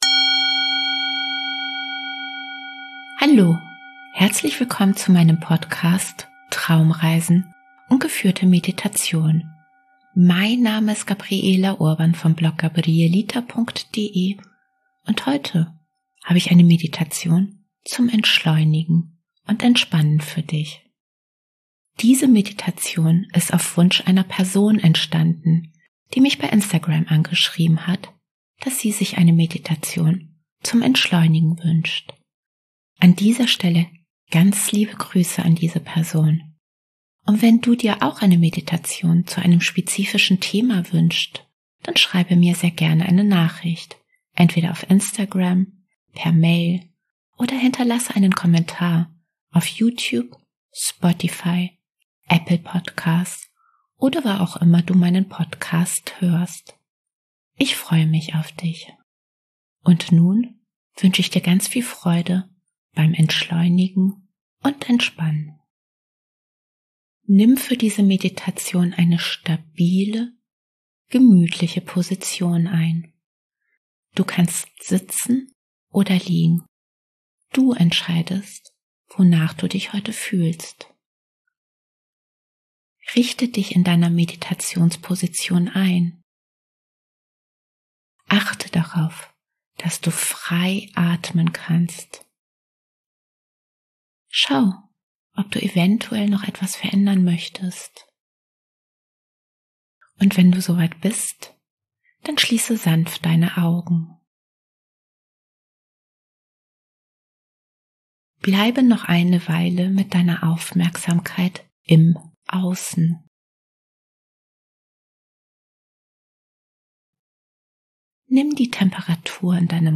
#042: Meditation zum Entschleunigen und Entspannen